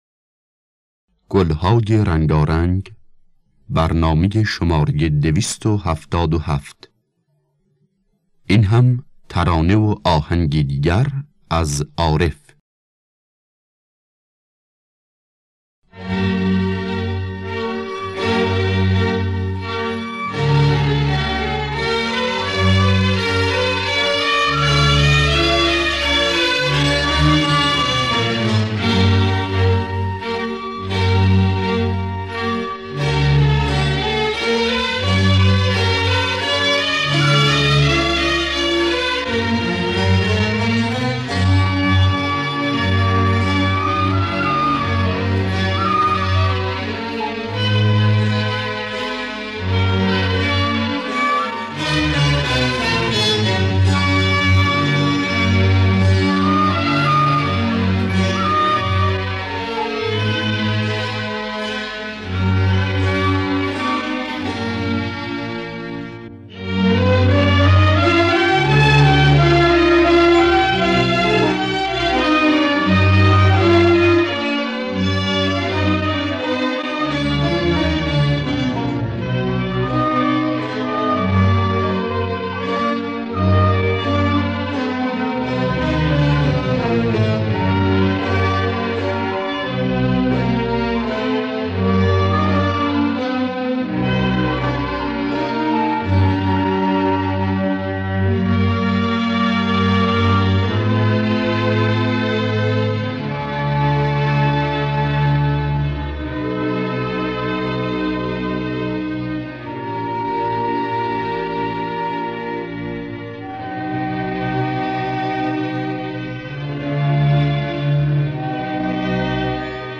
در دستگاه سه‌گاه